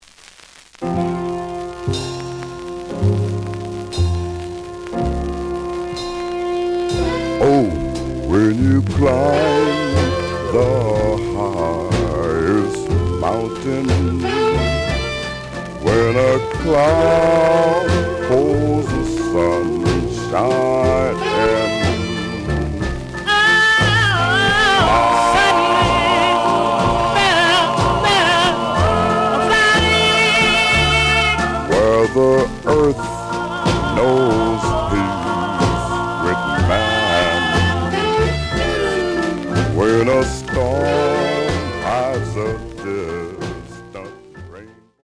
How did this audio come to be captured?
The audio sample is recorded from the actual item. Some noise on B side.